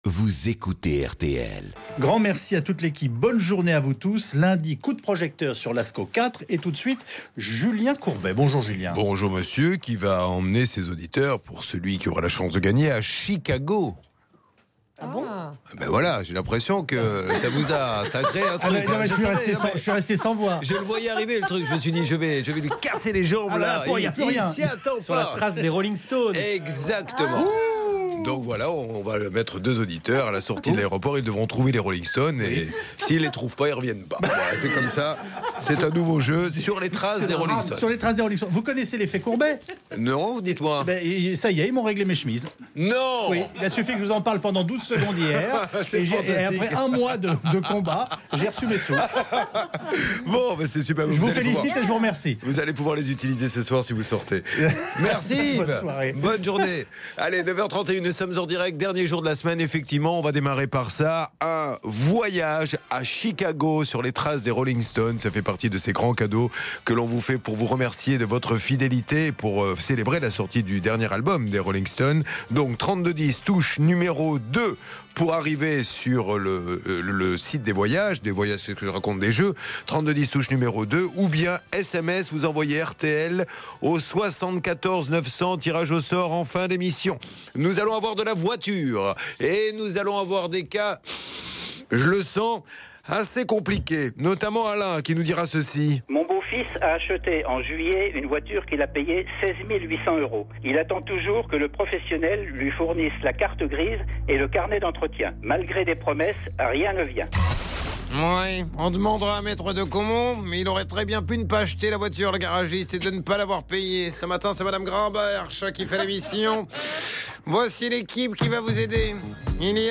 était l’invité de Julien Courbet sur RTL dans son émission « Ça peut vous arriver » le 9 décembre 2016.